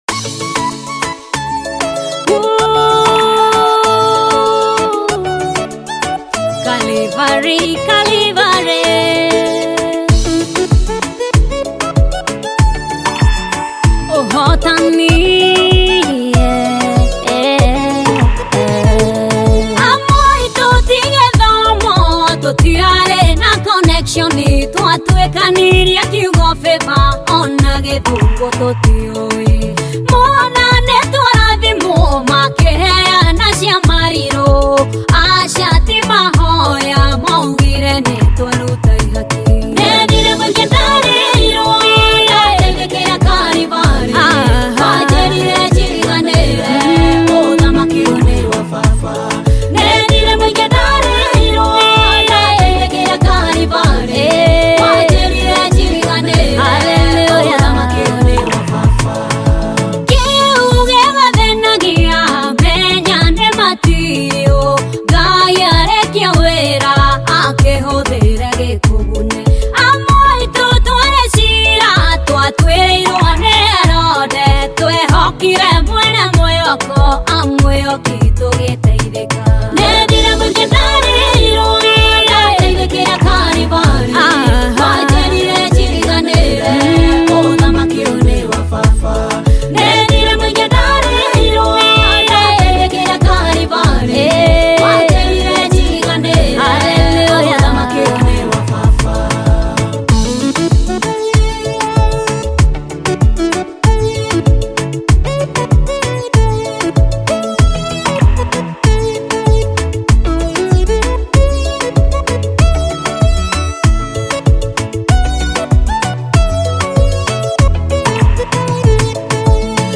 Kenyan Gospel Music